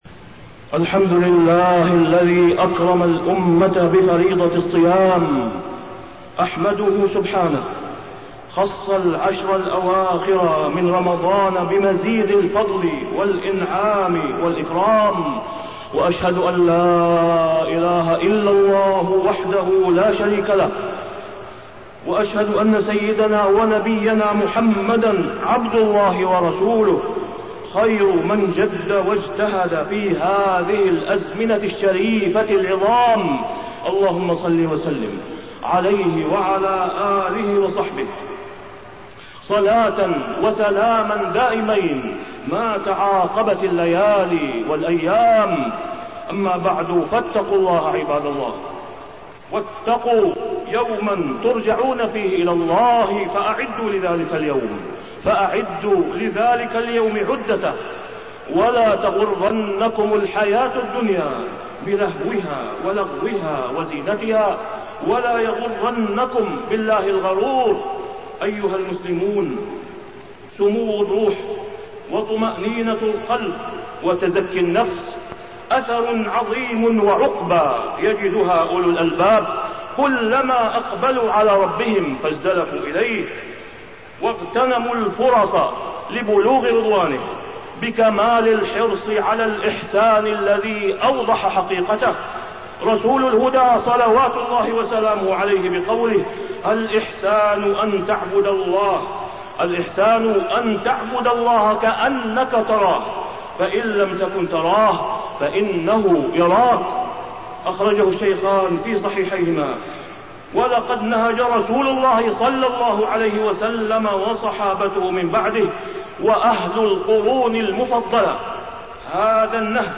تاريخ النشر ١٧ رمضان ١٤٣١ هـ المكان: المسجد الحرام الشيخ: فضيلة الشيخ د. أسامة بن عبدالله خياط فضيلة الشيخ د. أسامة بن عبدالله خياط العشر الأواخر